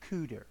or /ˈkʊdər/
US-Lisp-CDR.ogg.mp3